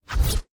Tab Select 14.wav